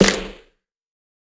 Snares
TC3Snare25.wav